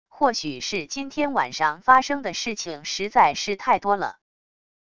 或许是今天晚上发生的事情实在是太多了wav音频生成系统WAV Audio Player